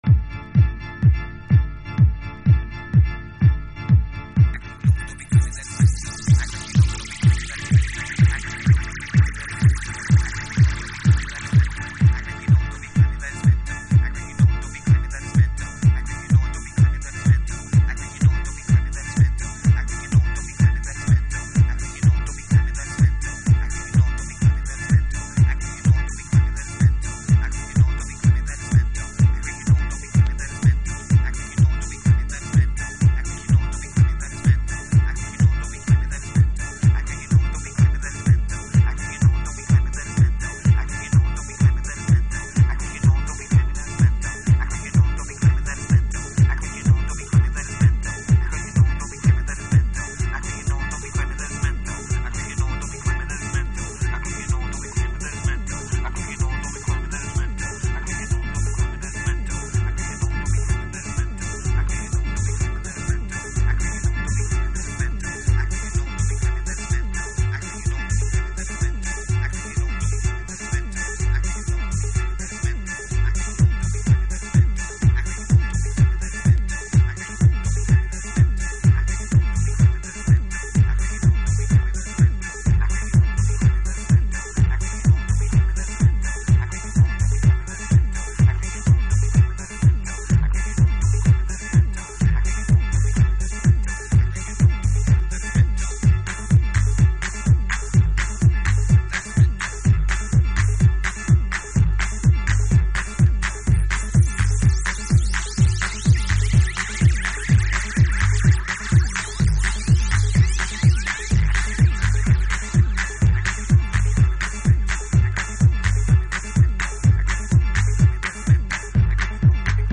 Chicago Oldschool / CDH